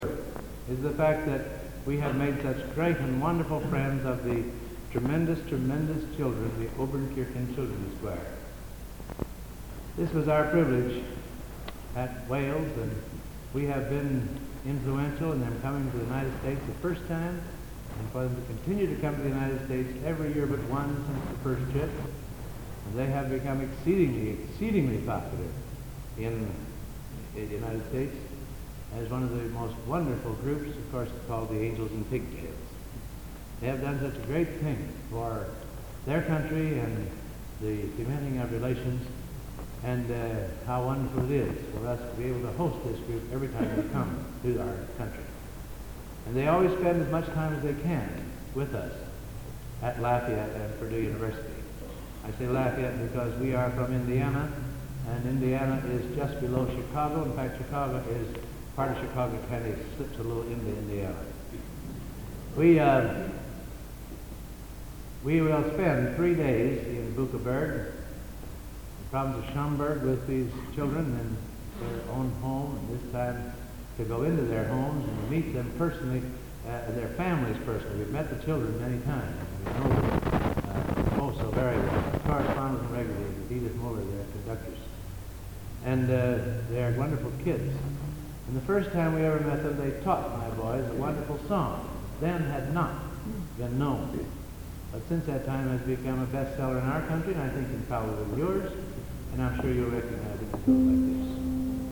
Location: Plymouth, England
Genre: | Type: Director intros, emceeing